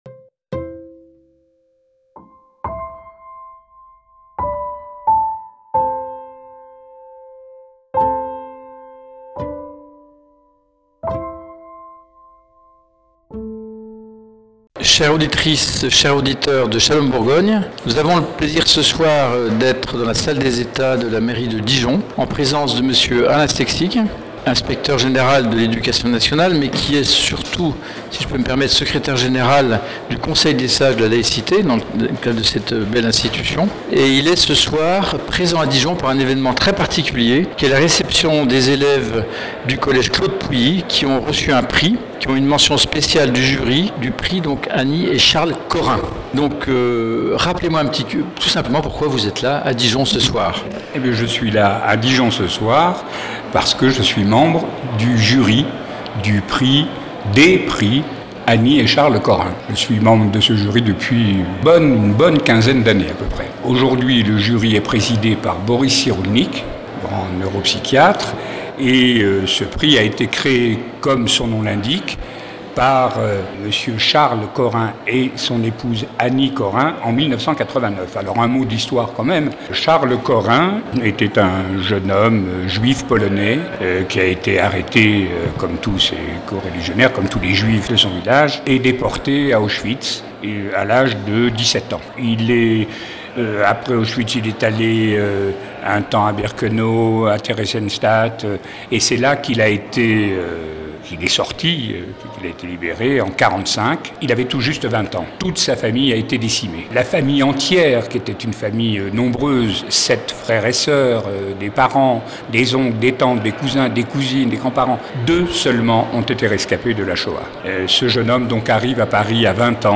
Vous pouvez entendre ici l'interview de Monsieur SEKSIG, inspecteur général, qui est par ailleurs Secrétaire général du Conseil des sages de la laïcité de l’Éducation Nationale.